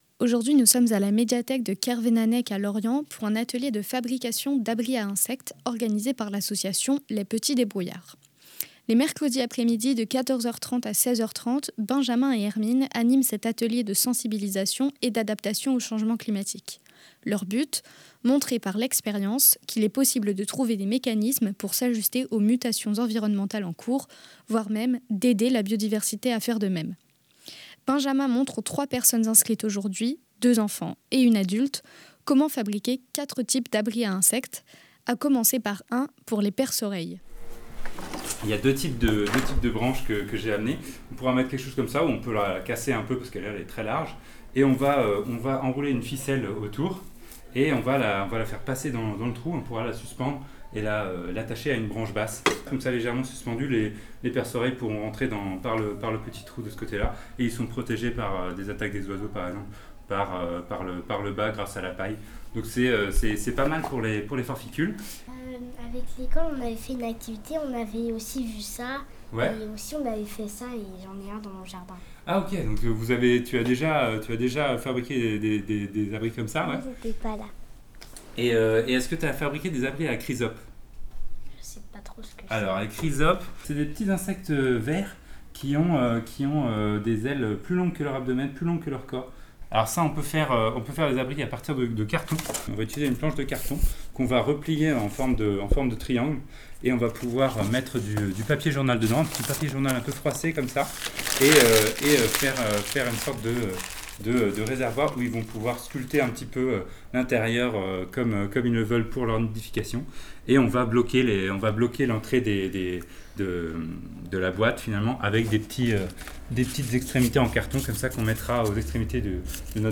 LEMRUB-Petits-debrouillards-adaptation-climat-Lorient-biodiversite-reportage.mp3